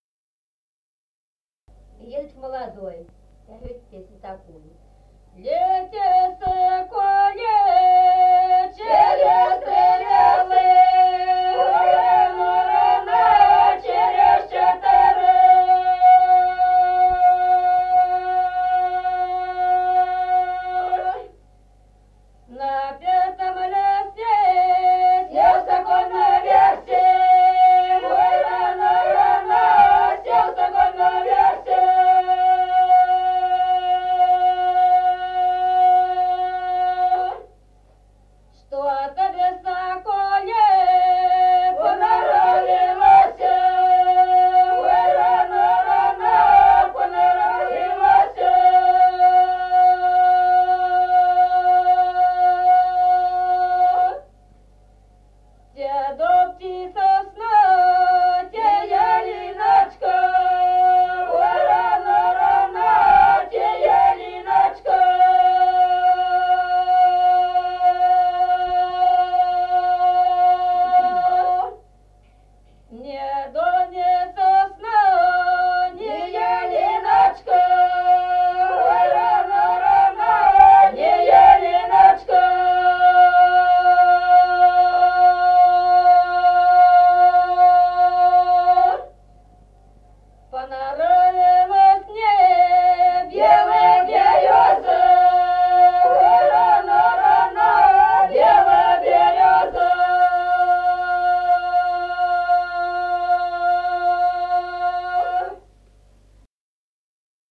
Народные песни Стародубского района «Летел соколик», свадебная.
с. Остроглядово.